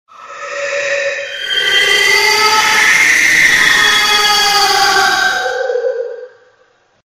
screaming-ghost-ringtone_14220.mp3